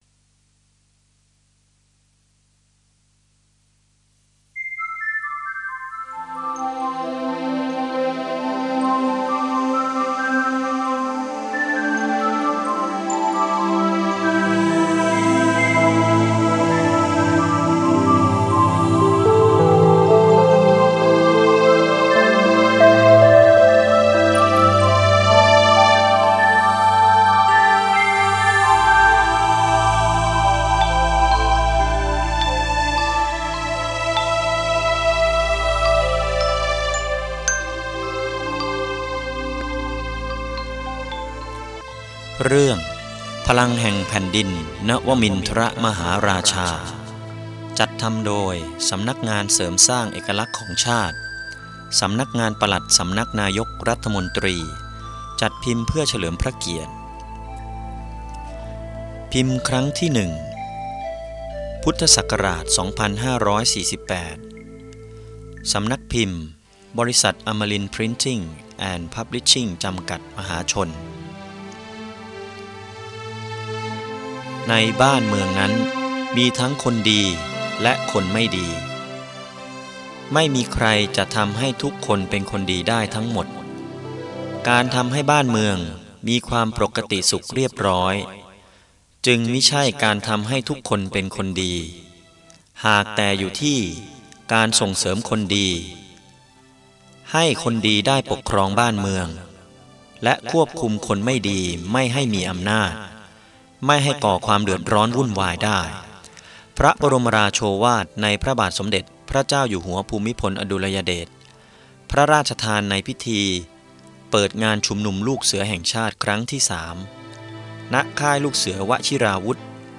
หนังสือเสียง จากหนังสือ พลังแห่งแผ่นดิน นวมินทรมหาราชา ตอนที่ 1 พระบรมราชสมภพ